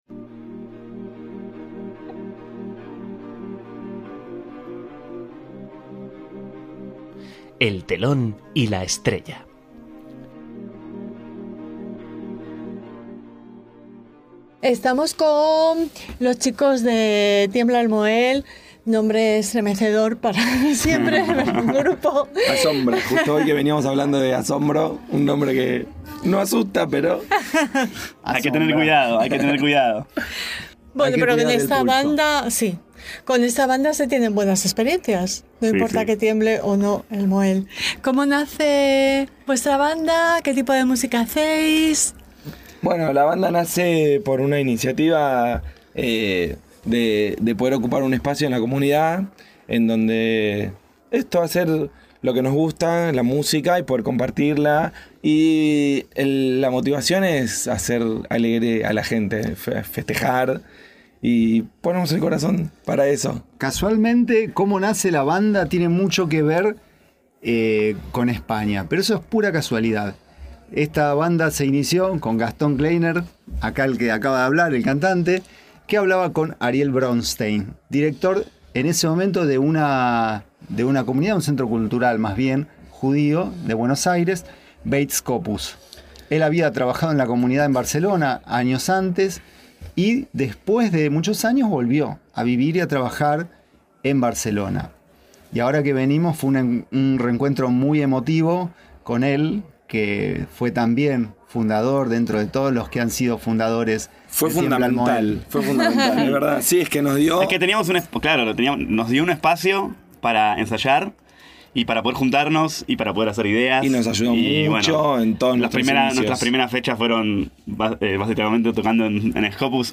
EL TELÓN Y LA ESTRELLA - Suena la música klezmer, tradicional, judía de Tiembla el mohel y ¡¡comienza la diversión!!